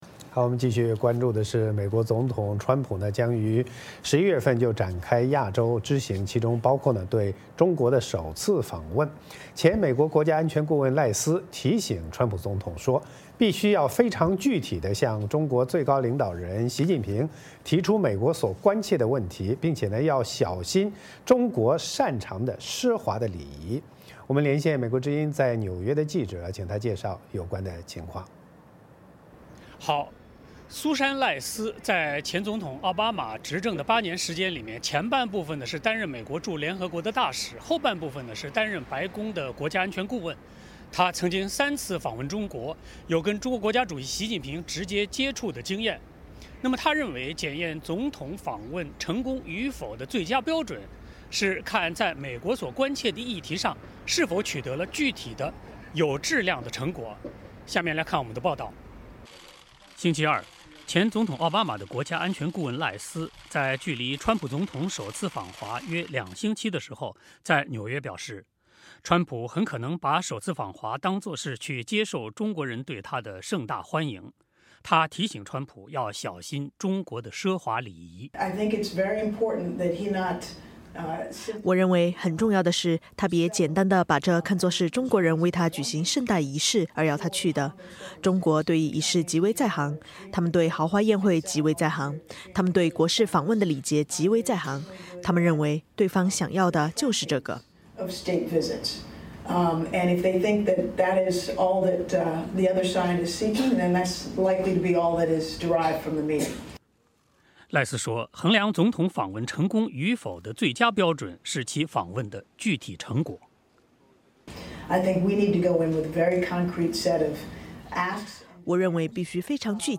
VOA连线：赖斯提醒川普小心北京的奢华礼仪